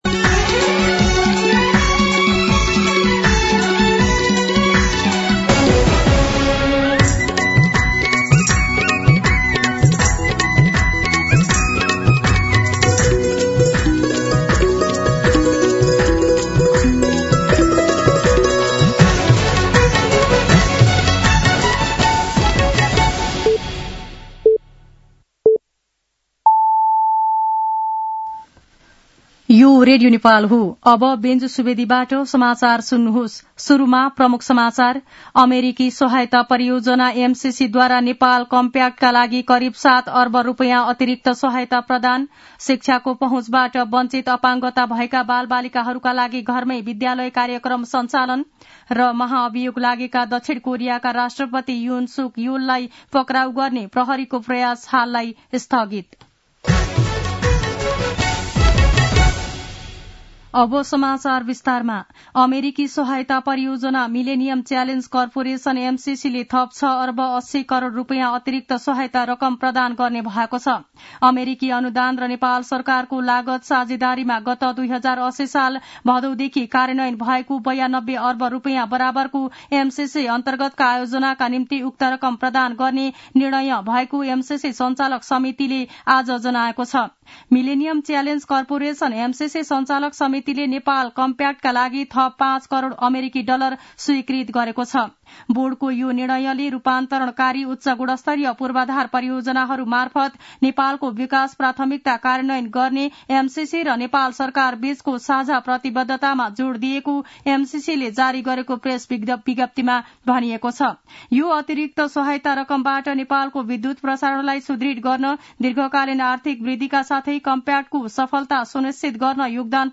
दिउँसो ३ बजेको नेपाली समाचार : २० पुष , २०८१
3-pm-nepali-news.mp3